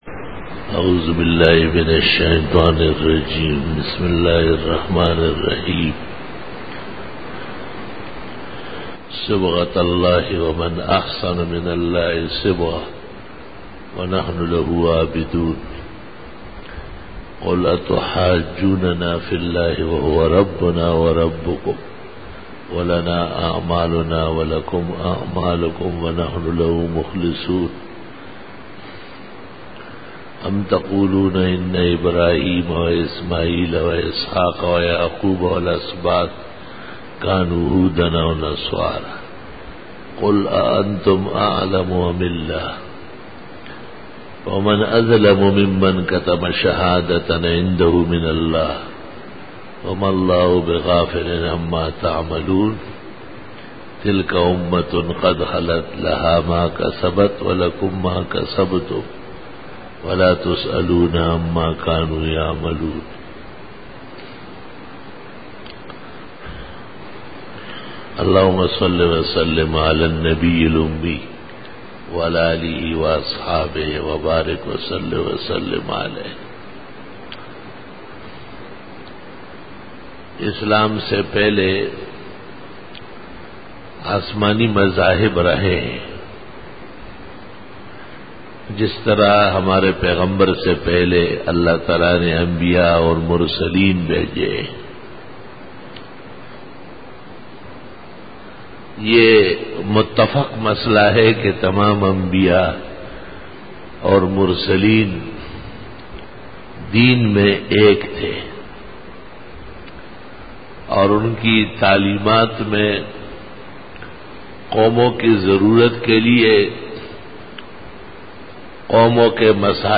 22_Bayan e Juma tul Mubarak 7-june- 2013
Khitab-e-Jummah 2013